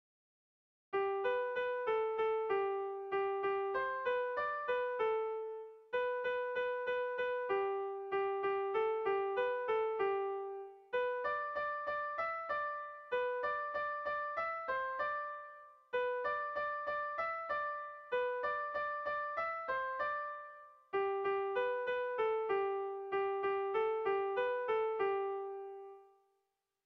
Kontakizunezkoa
ABDDB